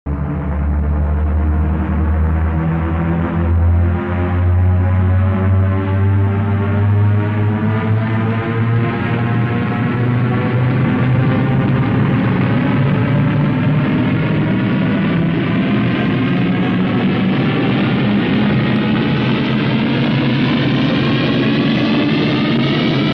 USS Enterprise NCC 1701-E does a warp flyby with TOS warp sound effects